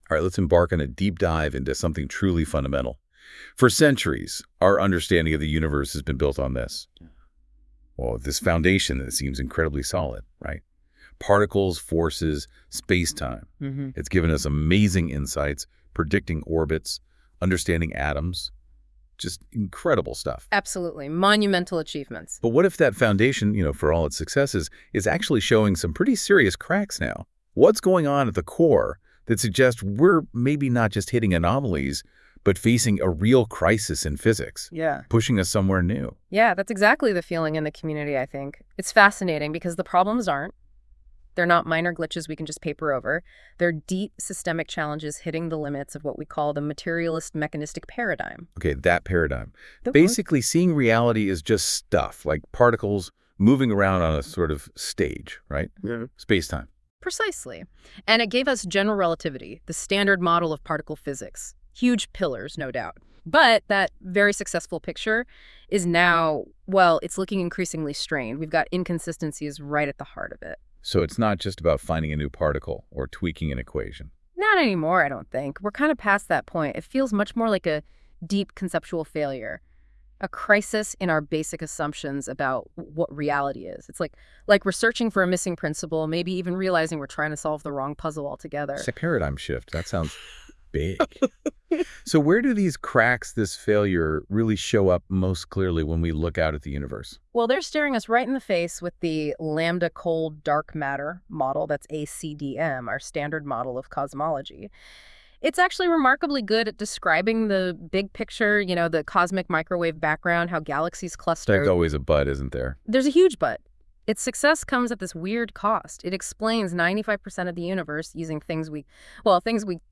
A comprehensive audio presentation